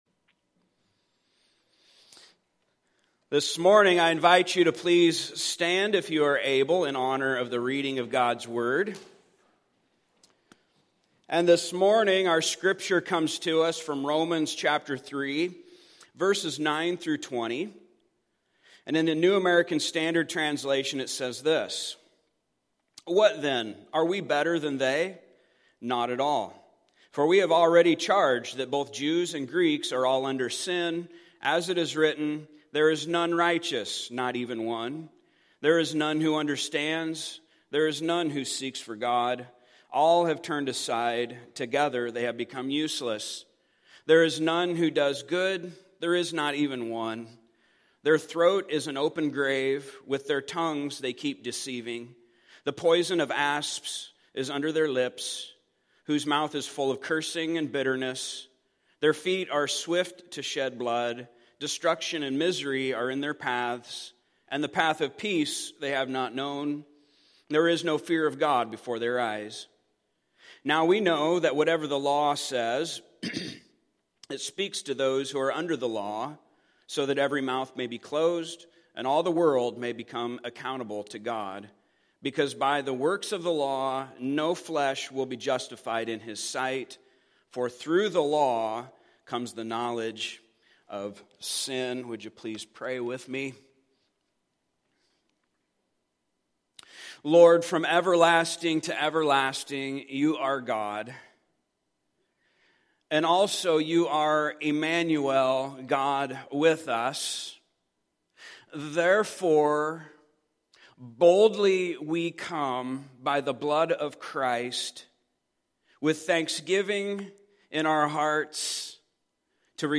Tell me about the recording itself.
Passage: Romans 3:9-20 Service Type: Sunday Morning